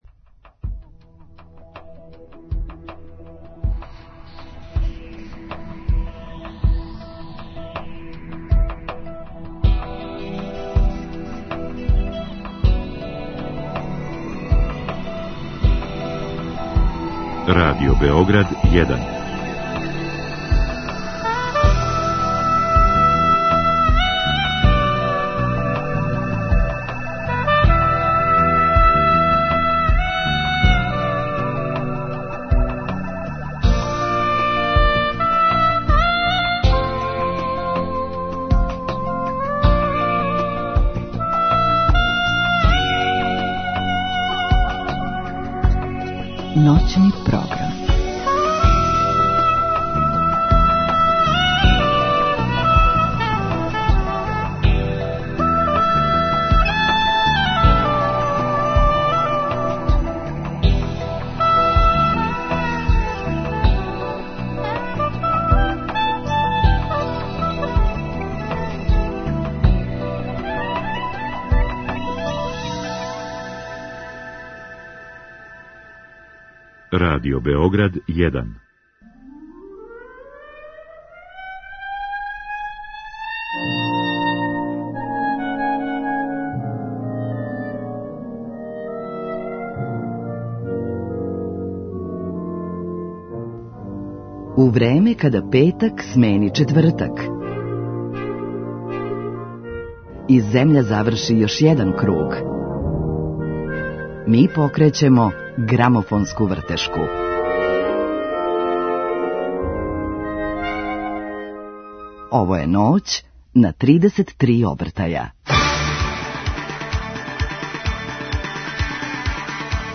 Ноћас ћете слушати плоче са музиком из филмова, али само оне на којима су нумере које су добиле златну статуу Оскара за најбољу оригиналну песму.